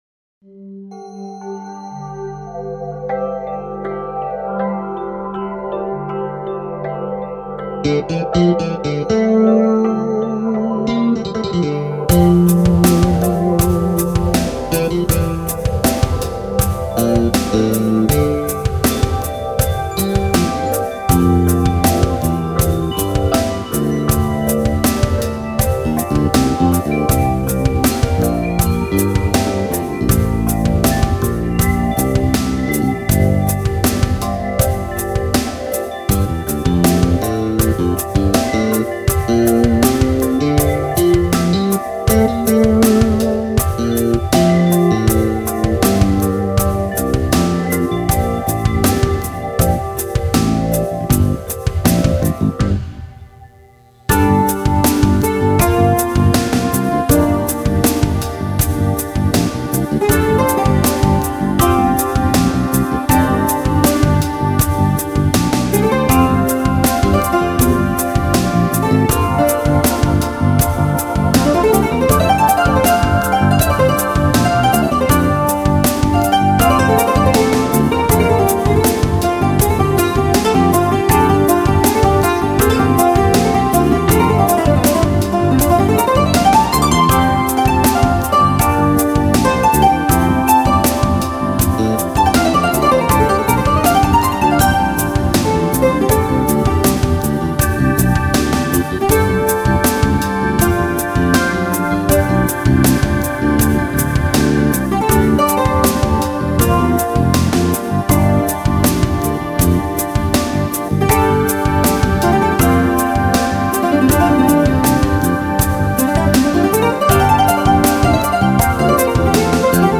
Keyboards, Accordion, Guitar, Ukulele, Bass Guitar, Drums
Trumpet, Flumpet and all Brass Instruments